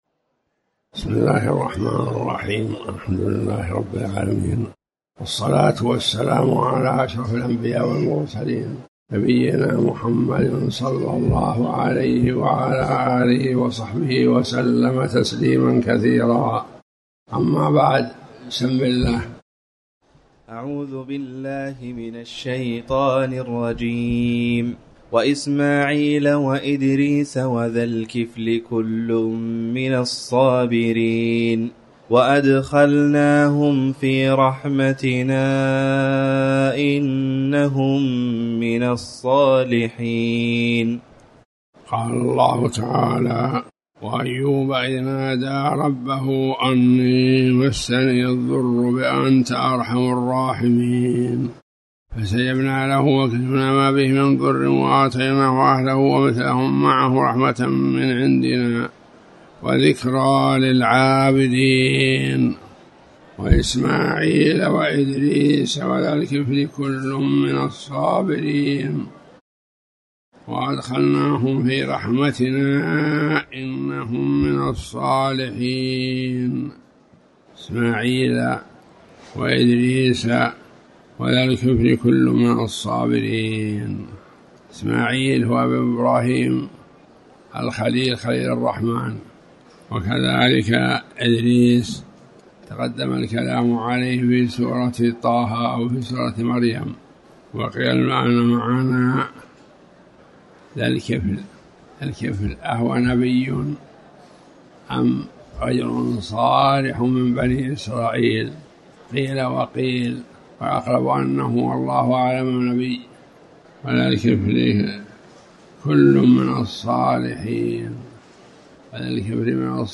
تاريخ النشر ٤ شعبان ١٤٤٠ هـ المكان: المسجد الحرام الشيخ